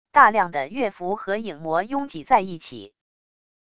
大量的月蝠和影魔拥挤在一起wav音频